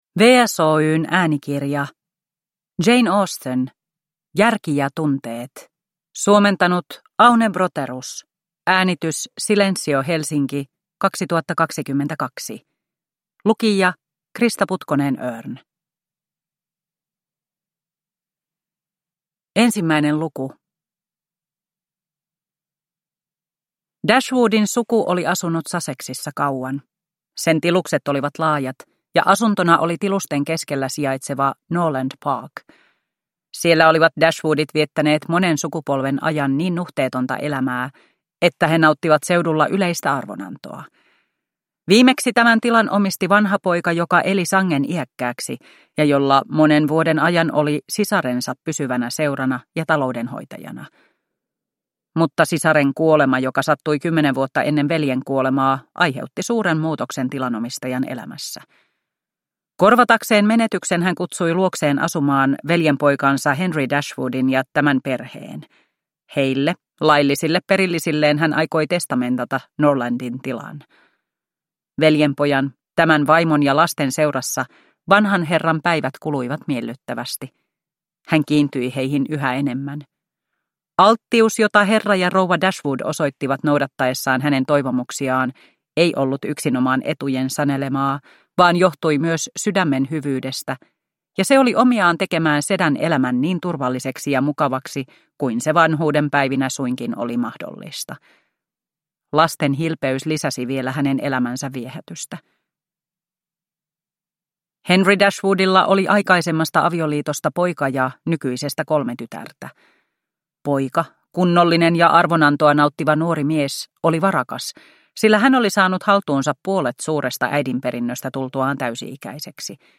Järki ja tunteet – Ljudbok – Laddas ner